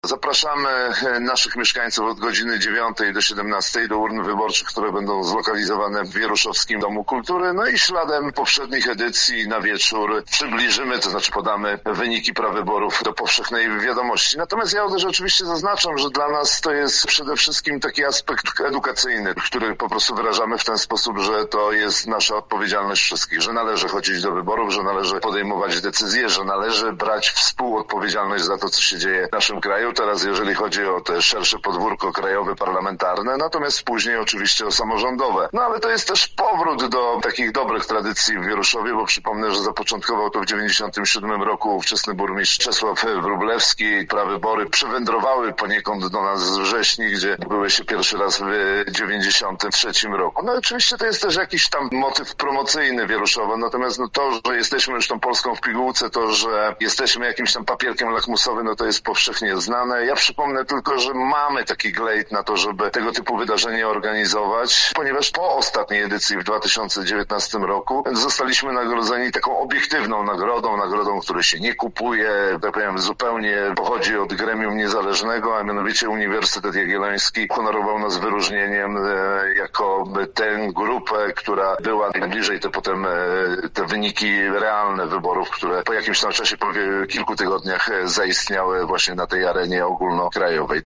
– mówił burmistrz Wieruszowa, Rafał Przybył.